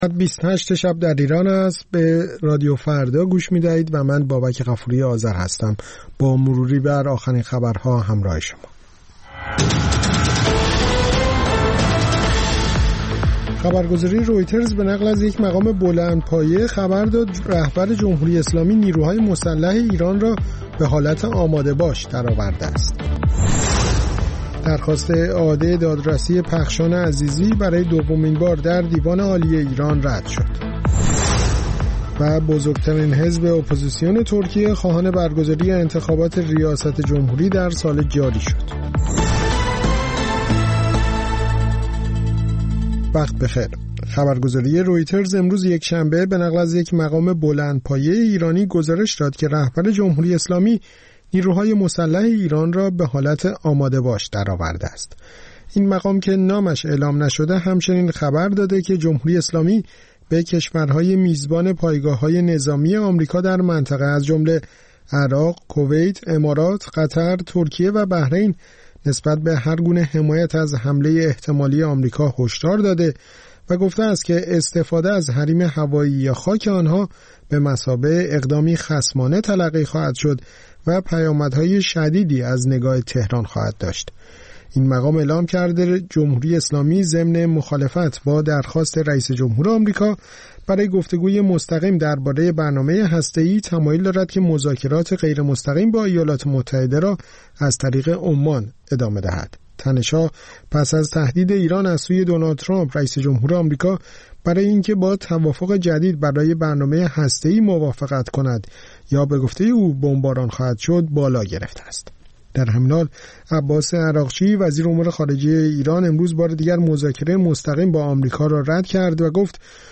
سرخط خبرها ۲۰:۰۰